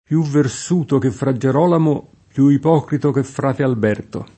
pLu vverS2to ke ffra JJer0lamo, pLu ip0krito ke ffr#te alb$rto] (Machiavelli); fra Ieronimo da Ferrara [